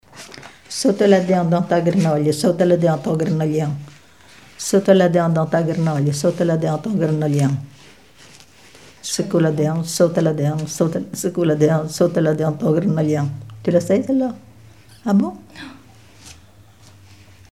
branle
Chants brefs - A danser
Témoignages sur les folklore enfantin
Pièce musicale inédite